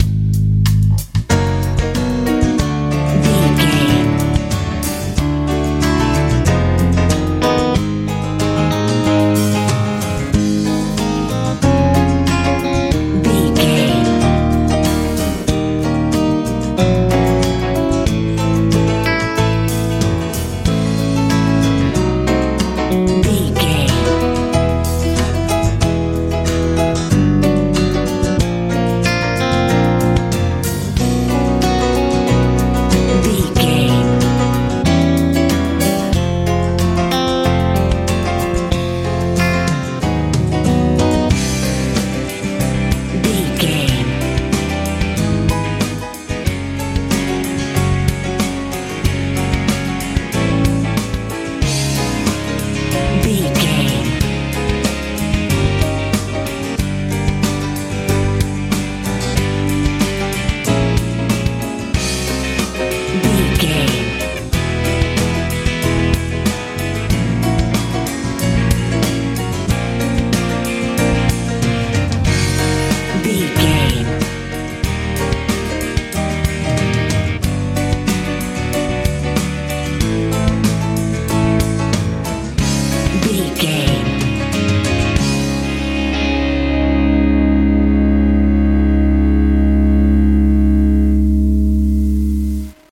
pop rock ballad
Ionian/Major
A♭
bright
bass guitar
drums
acoustic guitar
electric guitar
piano